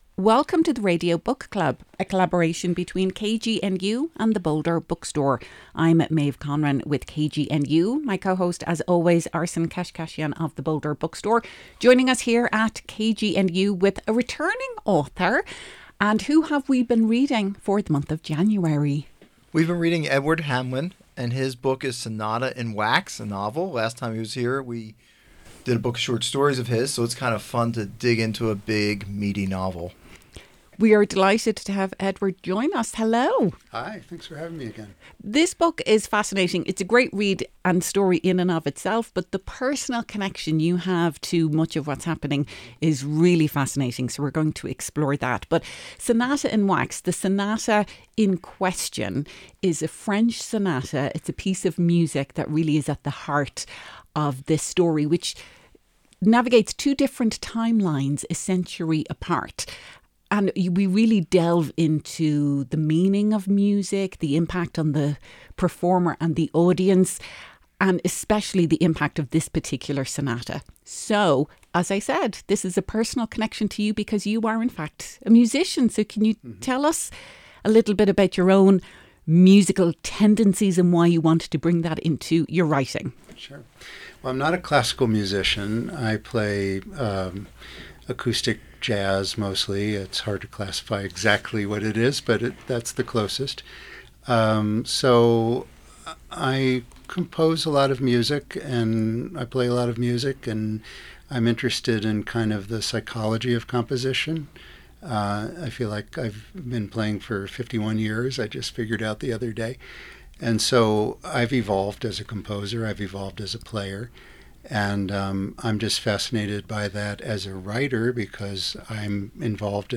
This interview was broadcast on KGNU at 9 am on Thursday, January 22, 2026.